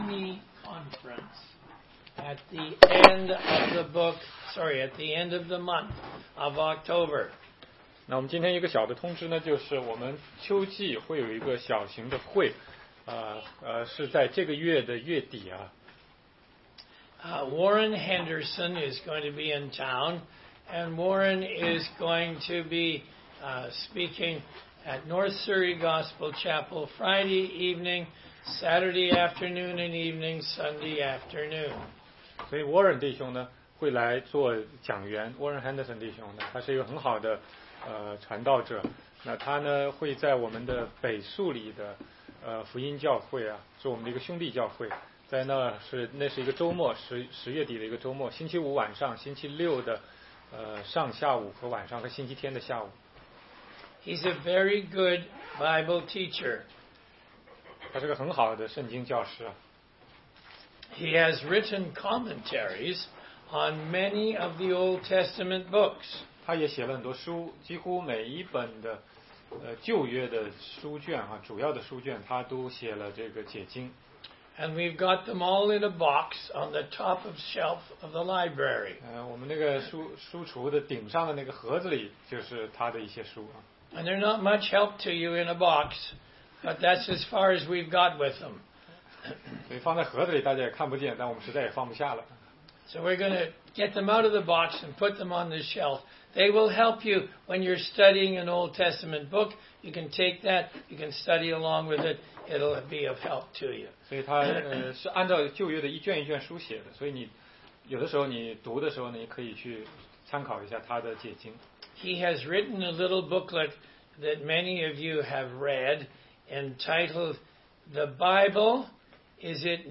16街讲道录音 - 哥林多前书6章9节-7章11节：要逃避淫行